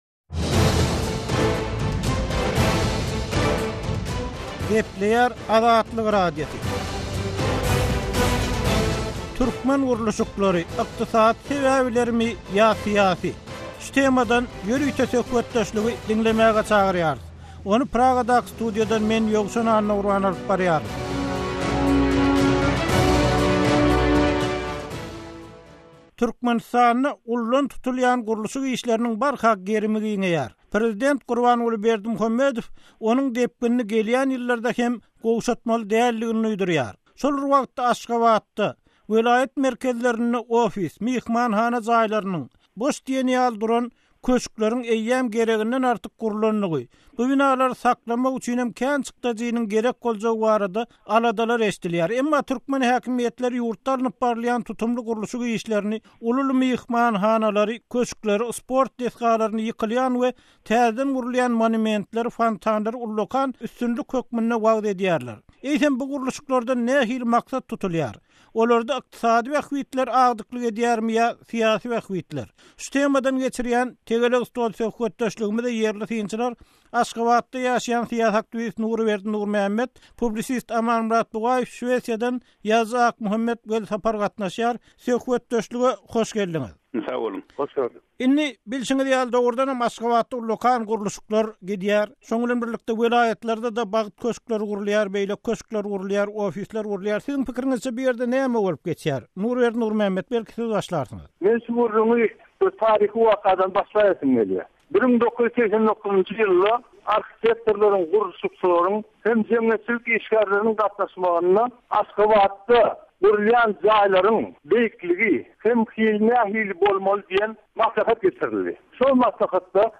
Tegelek stol: Gurluşyklaryň arkasyndaky sebäpler